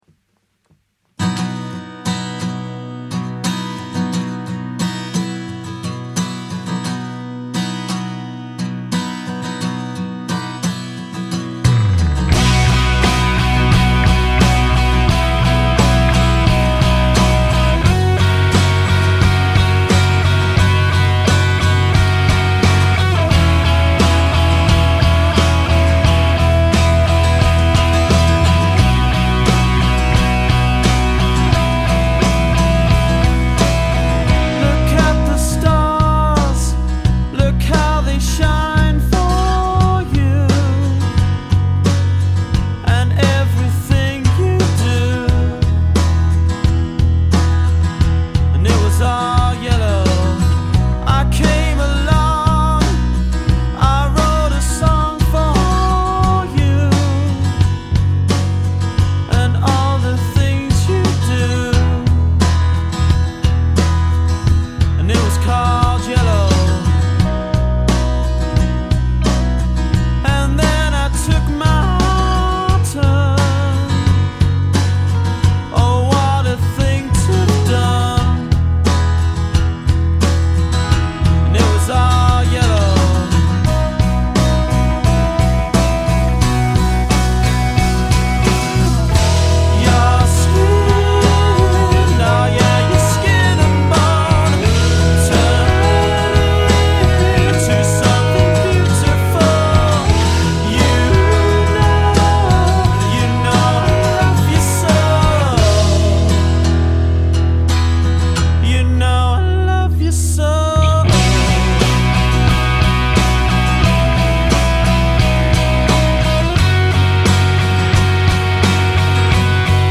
• 4-piece
Vocals / Guitar, Lead Guitar, Bass, Drums